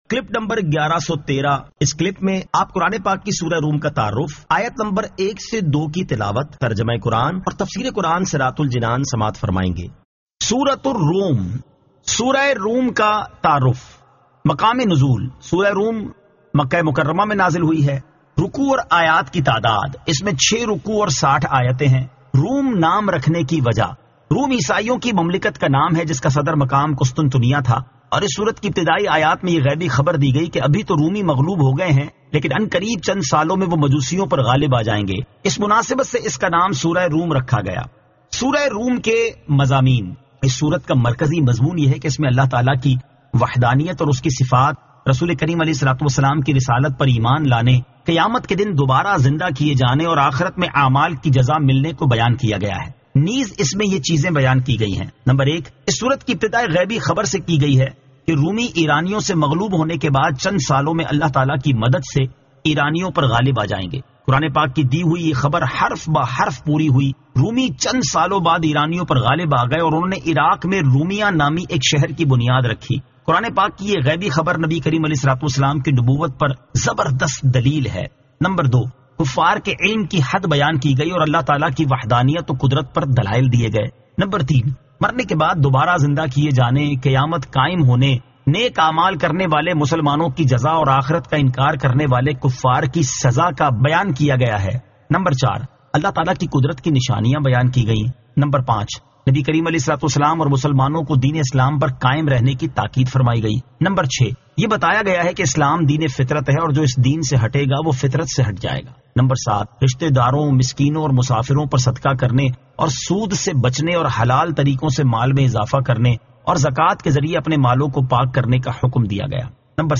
Surah Ar-Rum 01 To 02 Tilawat , Tarjama , Tafseer